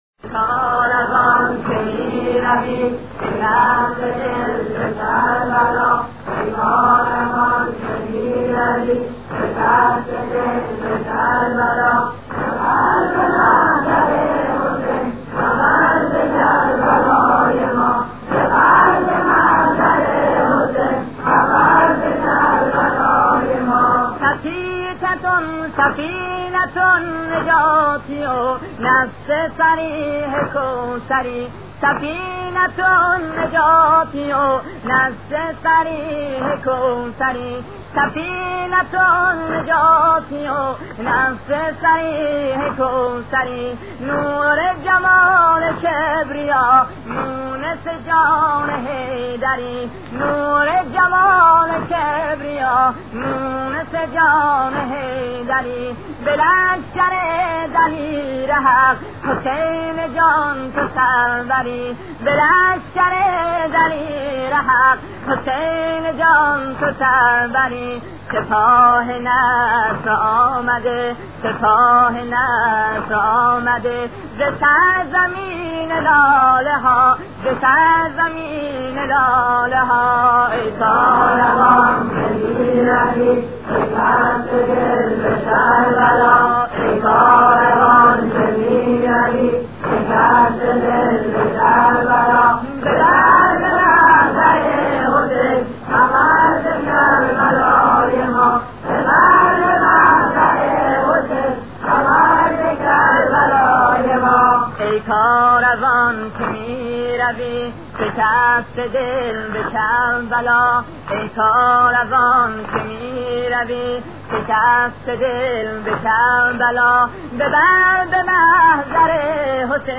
گلف چند رسانه‌ای صوت سرود ای کاروان که می روی شکسته دل به کربلا ای کاروان که می روی شکسته دل به کربلا مرورگر شما از Player پشتیبانی نمی‌کند.